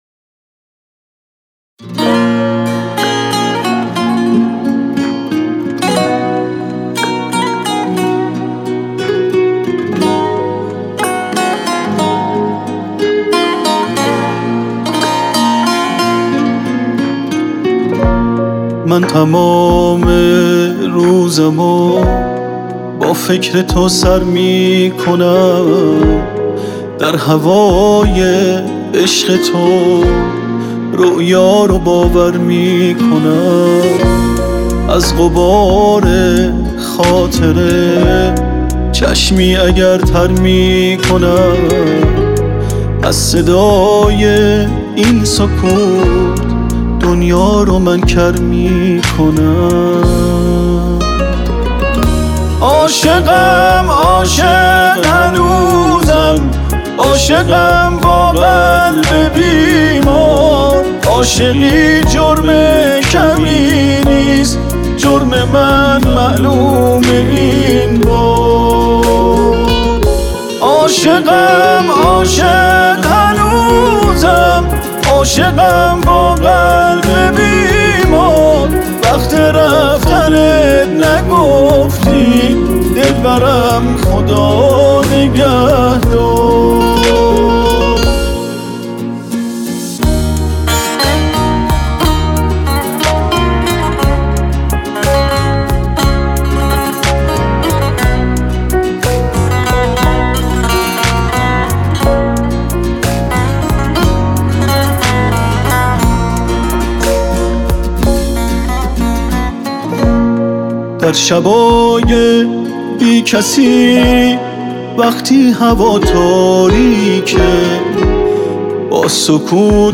صدای خوب و گرمی دارند